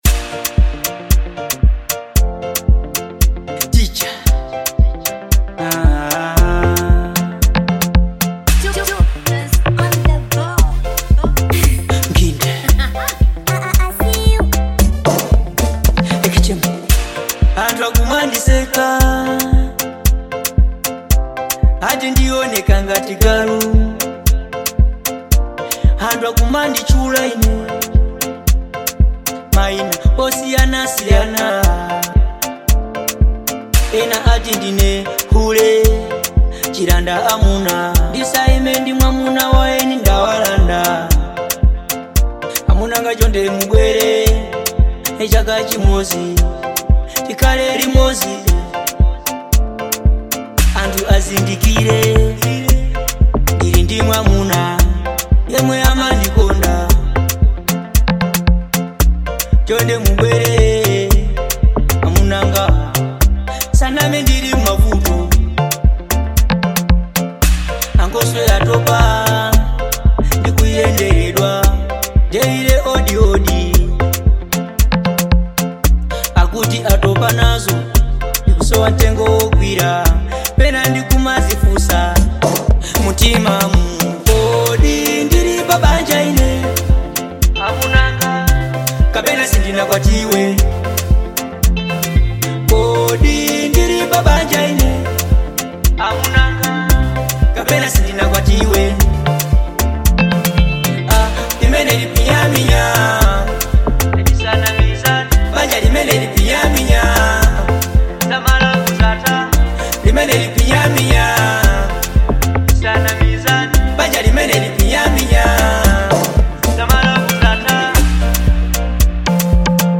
Genre : Local